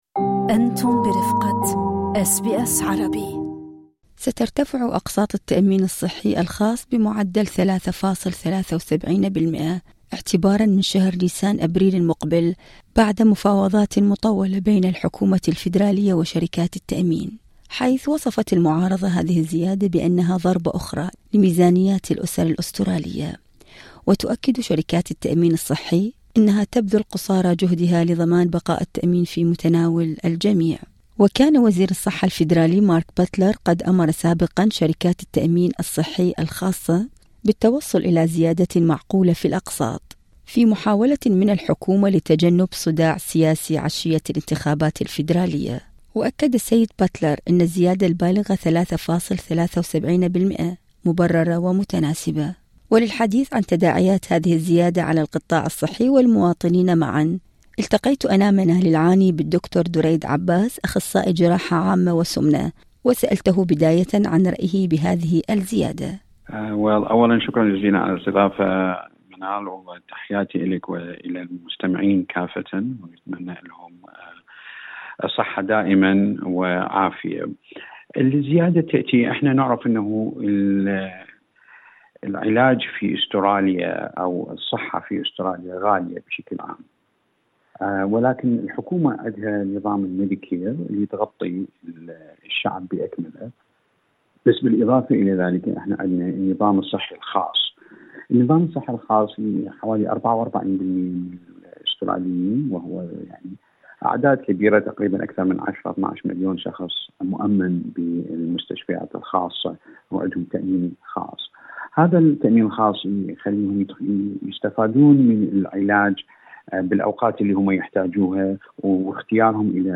أس بي أس عربي View Podcast Series